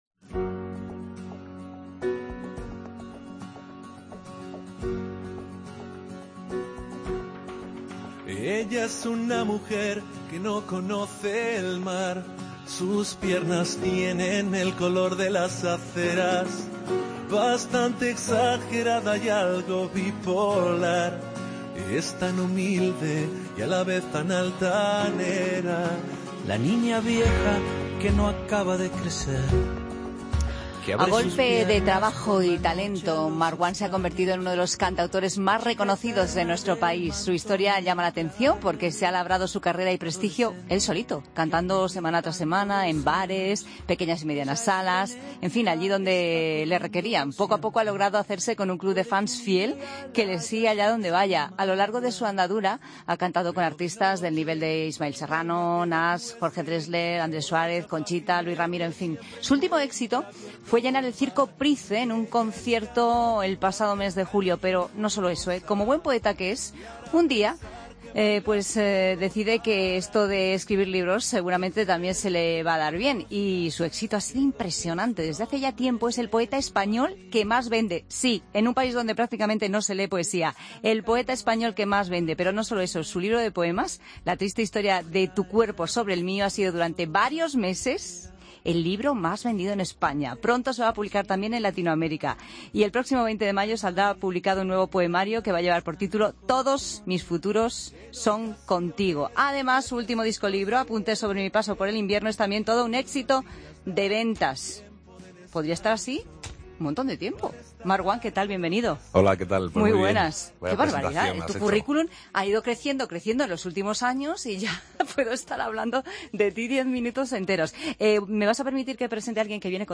Escucha la entrevista a Marwan en Fin de Semana